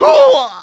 sm64_hurt.wav